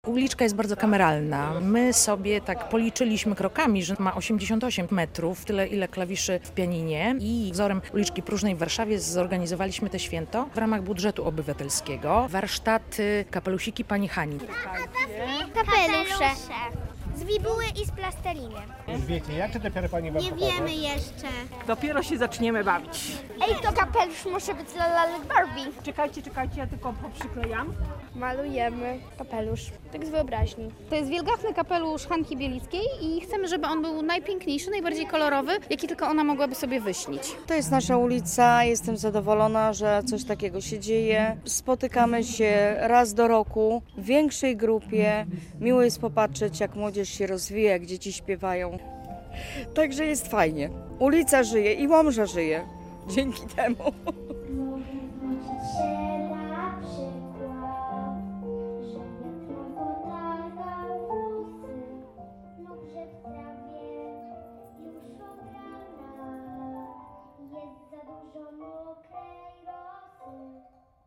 Święto ulicy Farnej - relacja